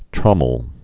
(trŏməl)